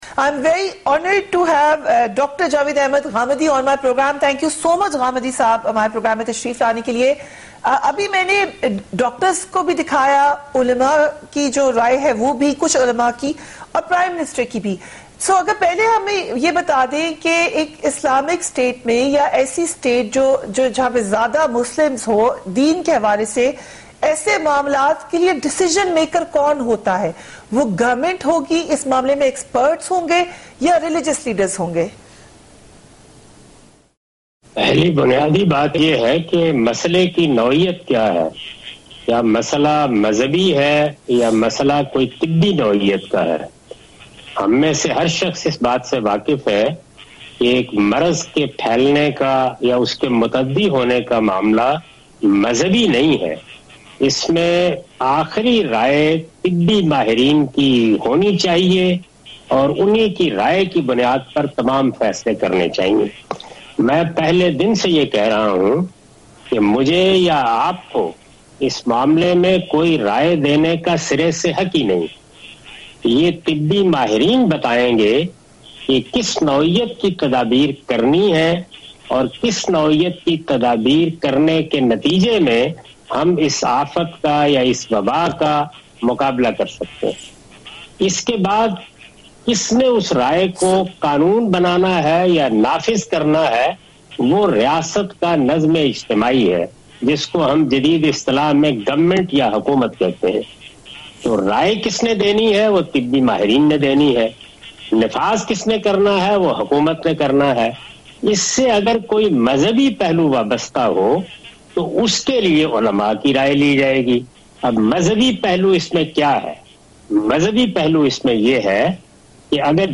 Javed Ahmad Ghamidi answers some important question about corona virus on Abb Takk News, Tonight with Fereeha 24 April 2020.